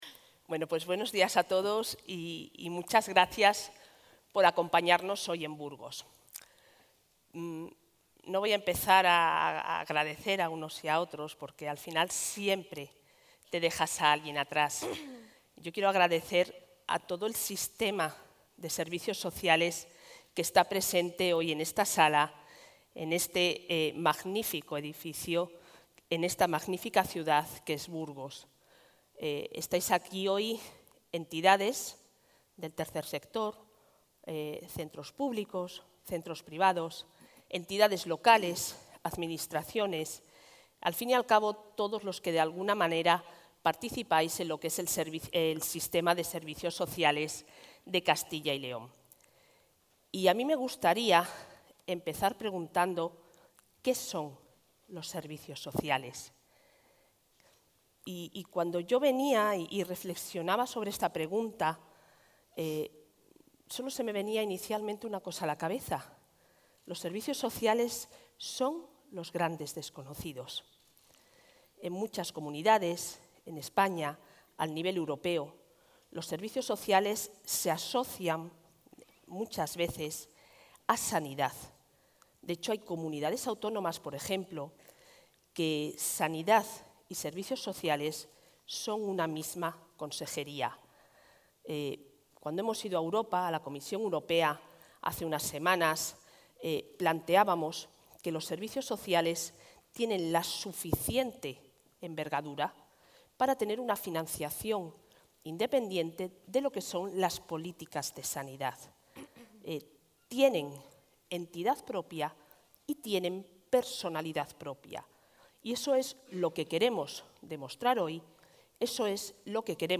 Intervención de la consejera